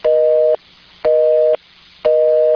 Ringetone Telefon optaget
Kategori Mobiltelefon